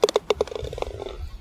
ballInHole.wav